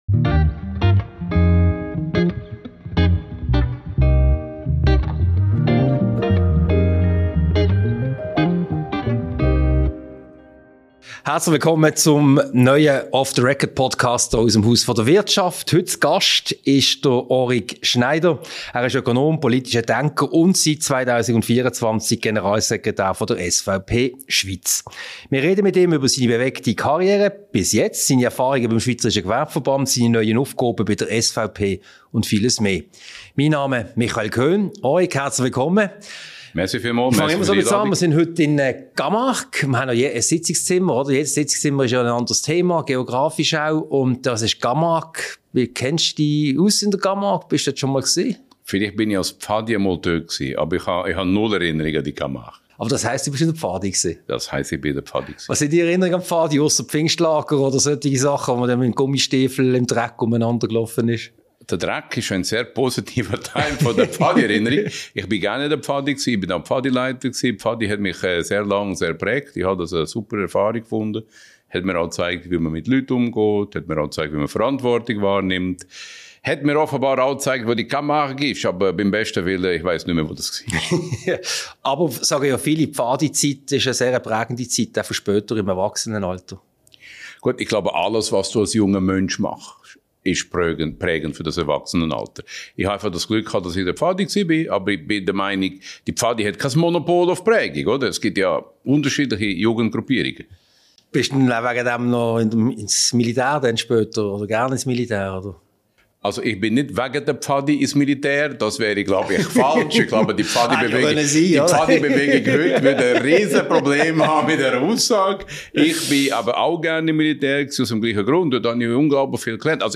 Diese Podcast-Ausgabe wurde als Video-Podcast im Sitzungszimmer Camargue im Haus der Wirtschaft HDW aufgezeichnet.